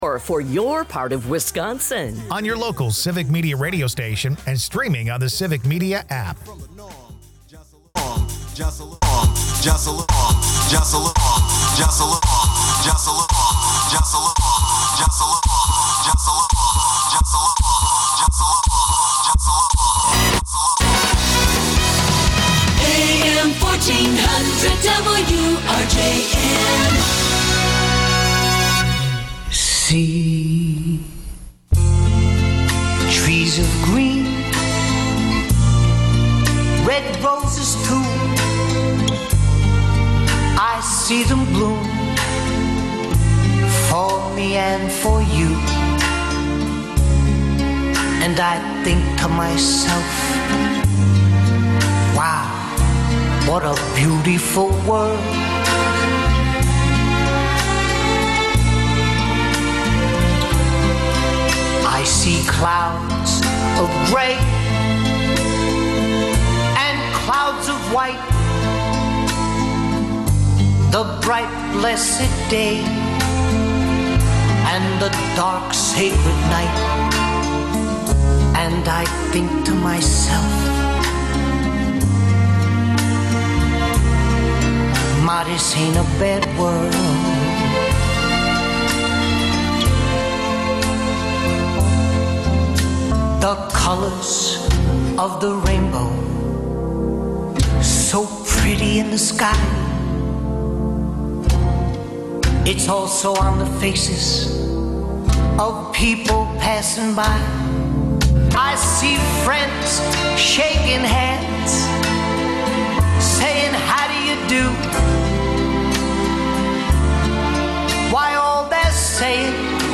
Broadcasts live 7 a.m. to noon Sunday mornings across Wisconsin.